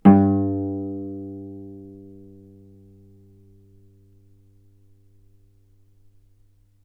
vc_pz-G2-ff.AIF